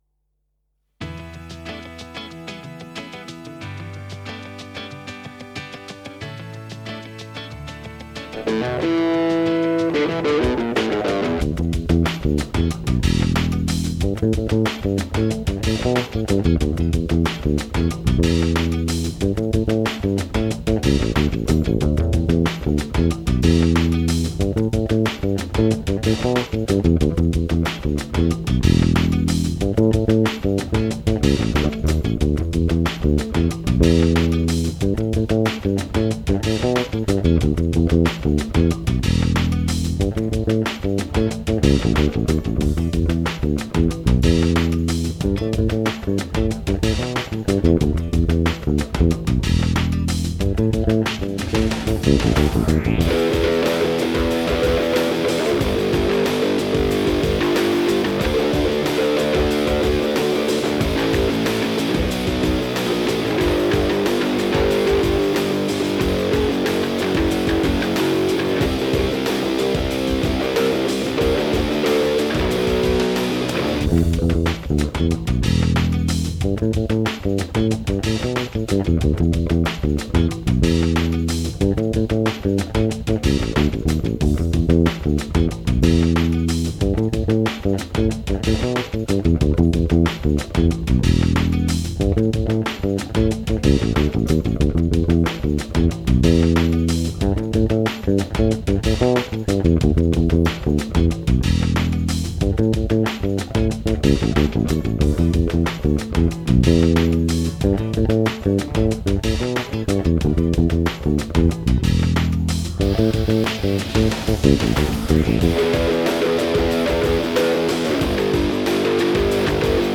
(instrumental)
Cover / Bass only